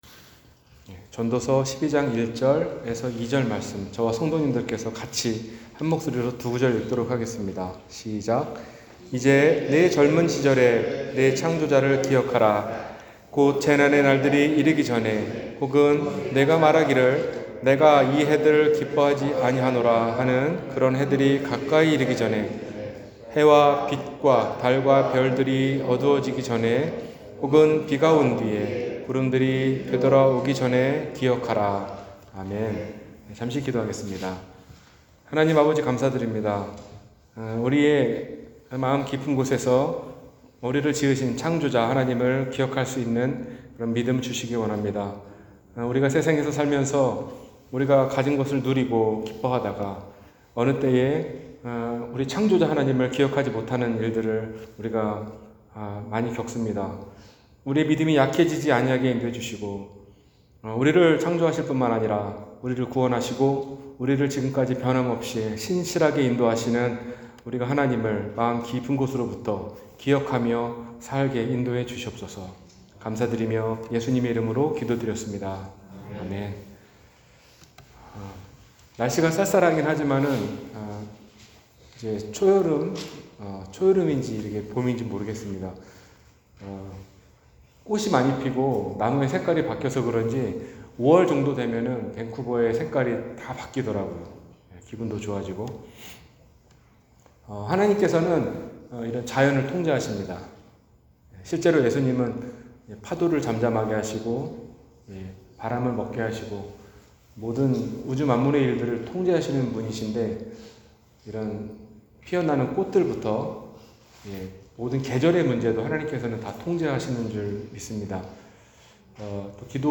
네 창조자를 기억하라 – 주일설교